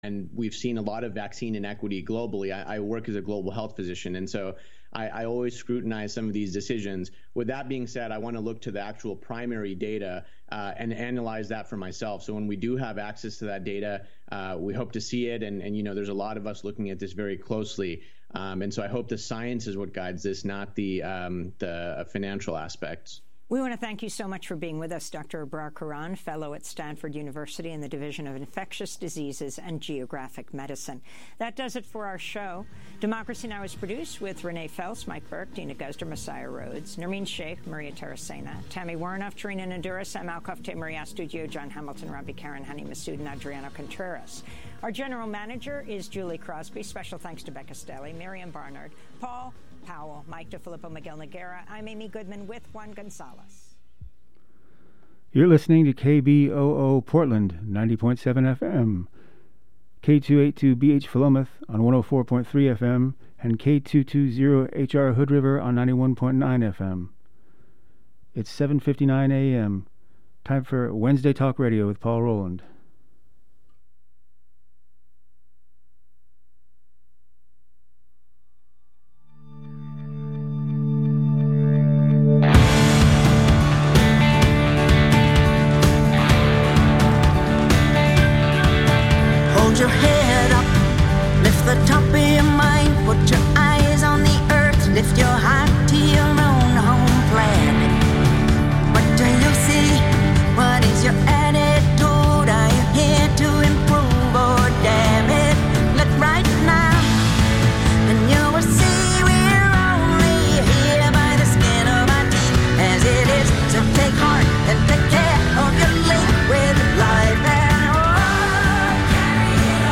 Open phone lines with host